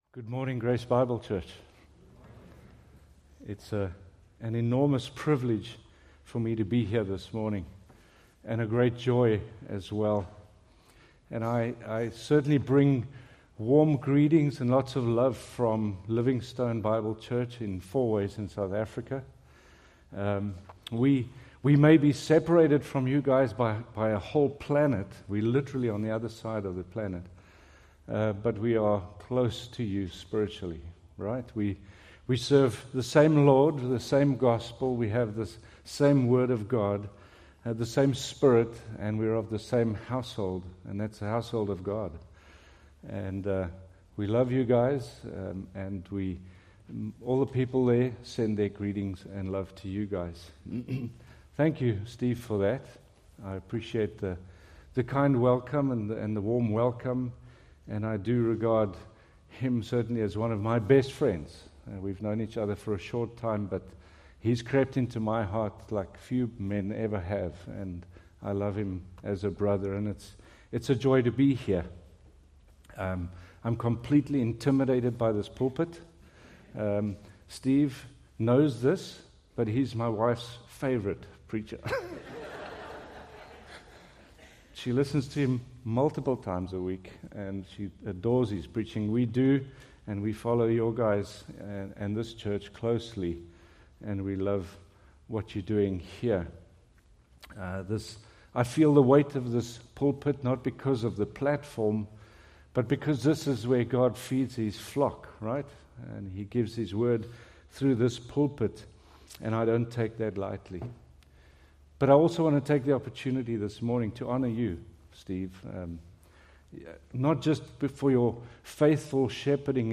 Preached March 15, 2026 from Selected Scriptures